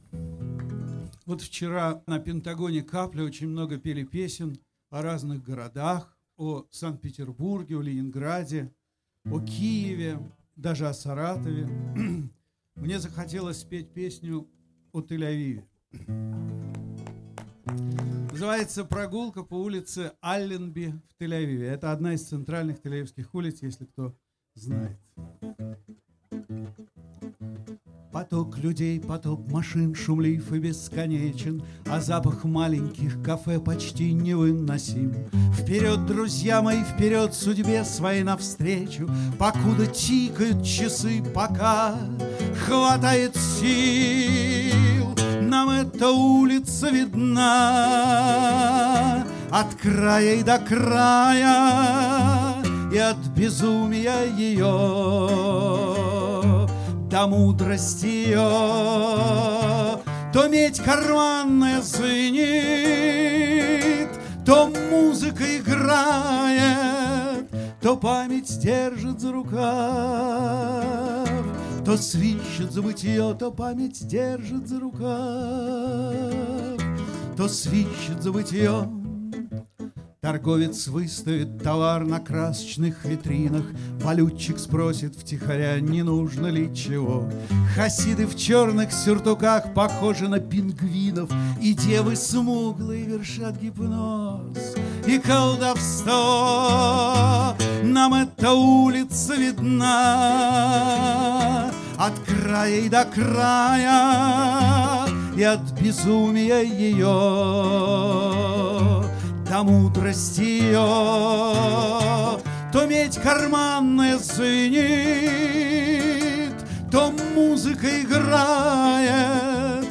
Субботний концерт